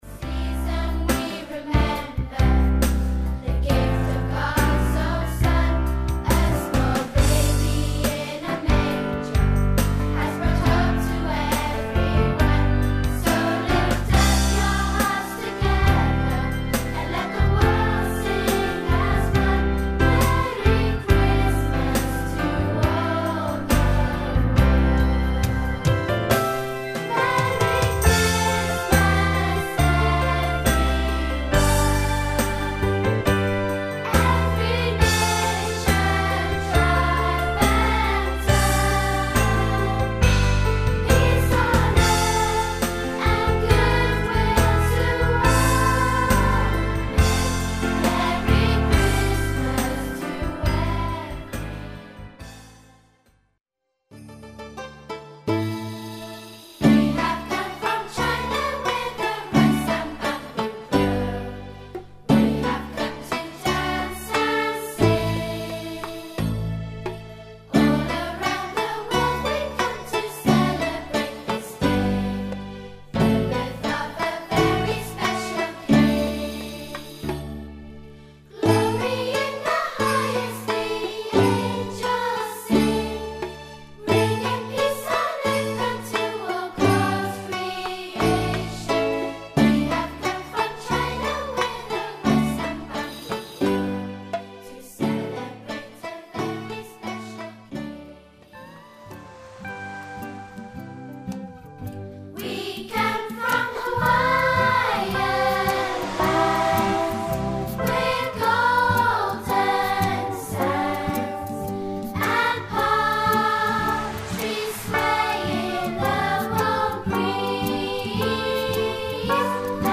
A Christmas musical
colourful and lively musical